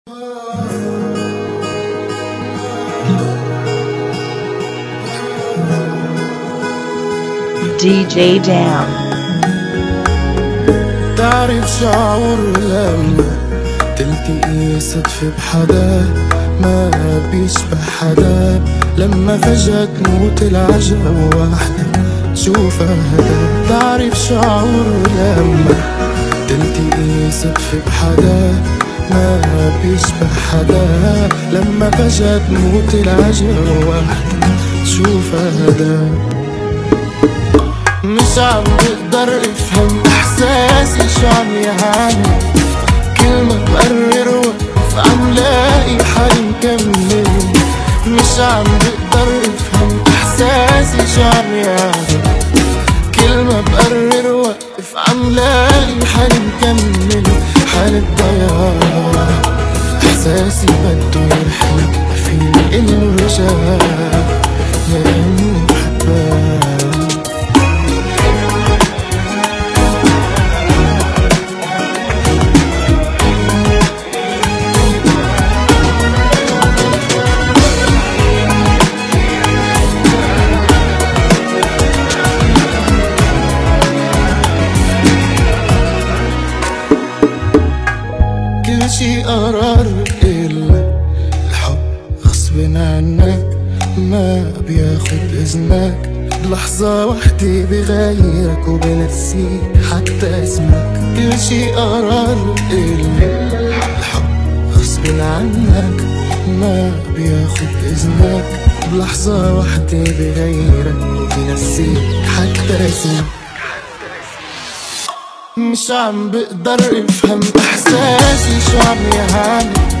96 BPM
Genre: Bachata Remix